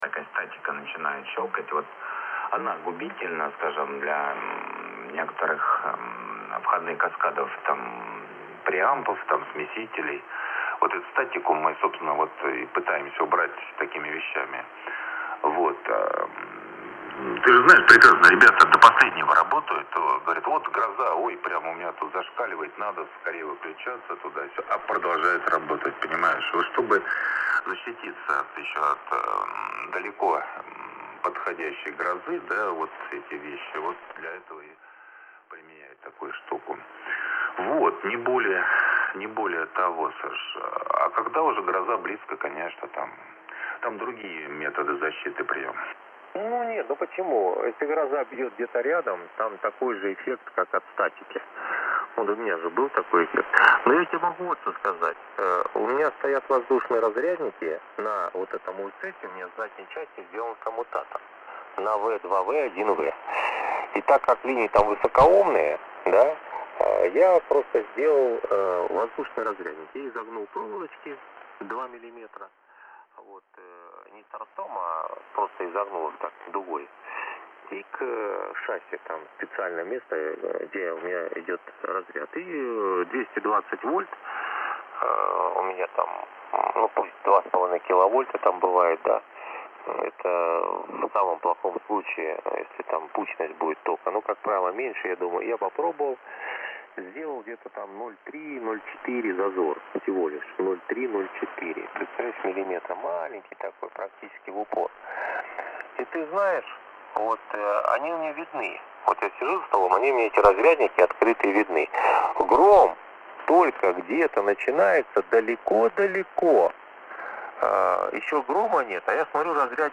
Свыше 50 %, при прослушивании сигналов которые по S-метру выше 7 баллов, искажения есть. Слушал через звуковую карту, вход которой нагружен на нагрузку 14 Ом.
Для детального анализа нужны хорошие приборы...При прослушивании файла, где качество улучшается, то это громкость на 45%, где ухудшается, то громкость более 55%.